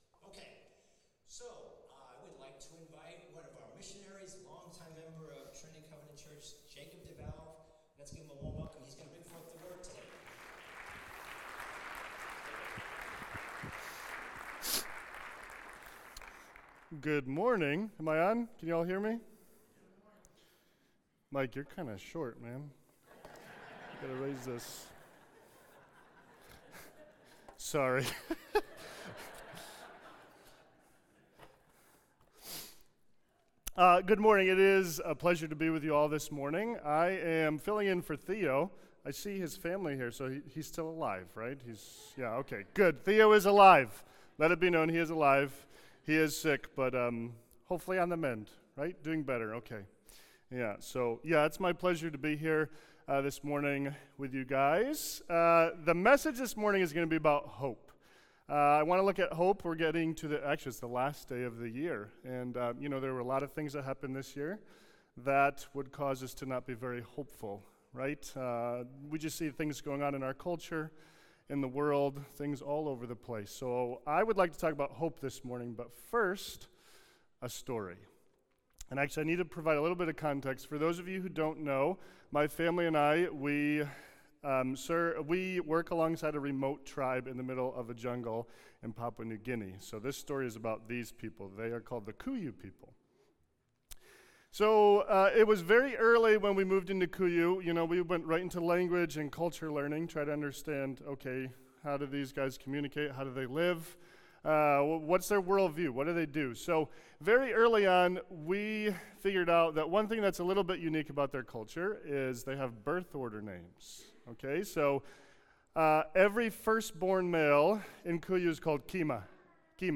New Years Eve Service
Guest Speaker